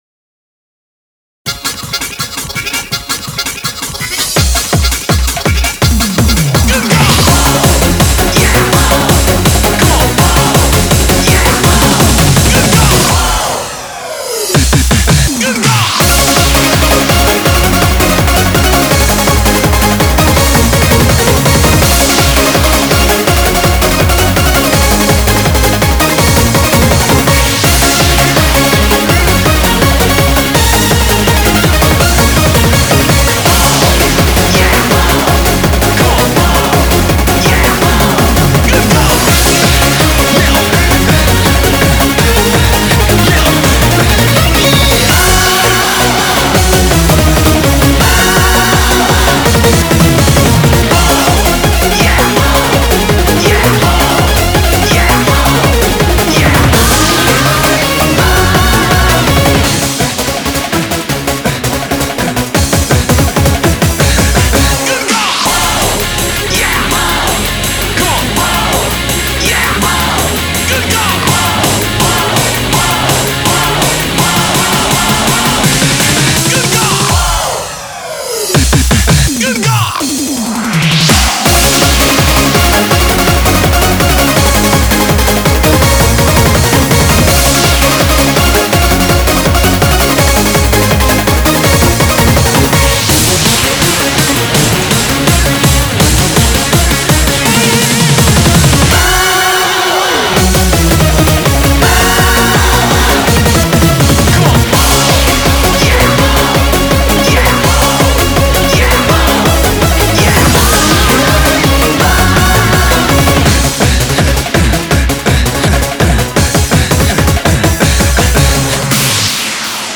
BPM165
Comments[HARD COUNTRY REMIX]